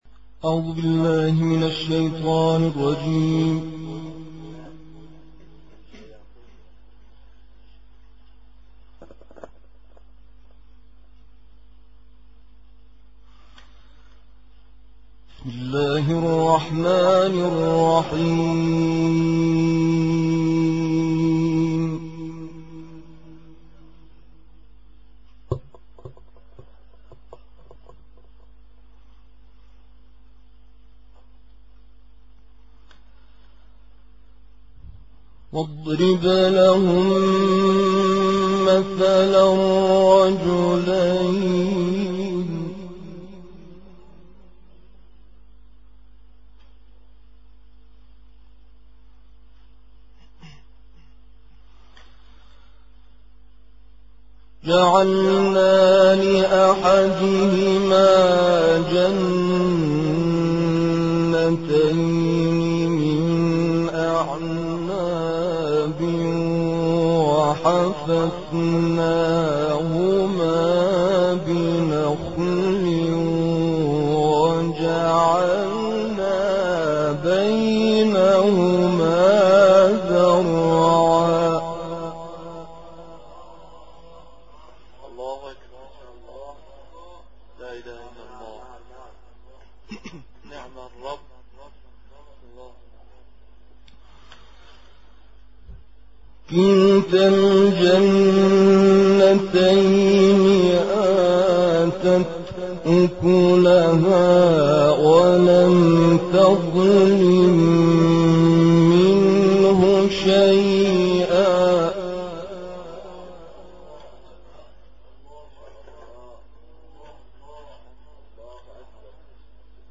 طهران ـ إکنا: تبثّ وکالة "إکنا" للأنباء القرآنية في المرحلة الثانیة من مشروع "التلاوة المحببة"، تلاوات لخمسة قراء ایرانیین وبامكان متابعي الوكالة الاستماع الى هذه التلاوات وإختيار التلاوة المحببة.